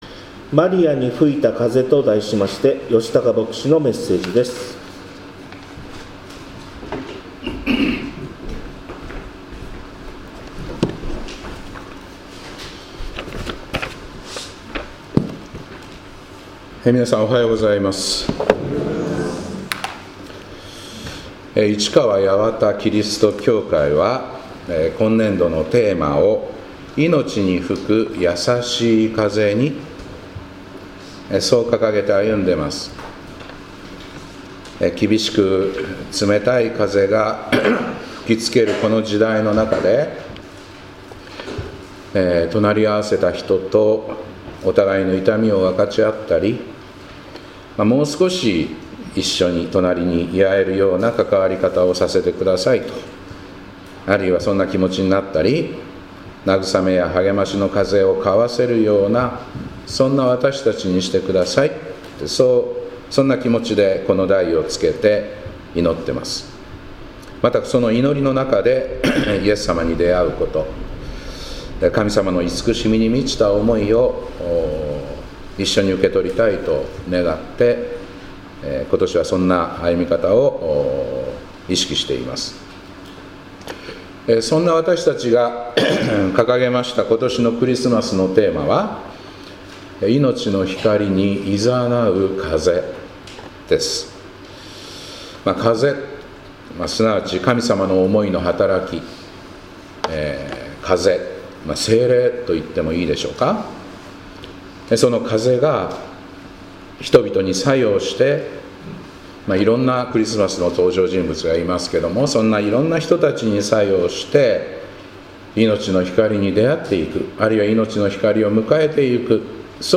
2025年12月7日礼拝「マリアに吹いた風」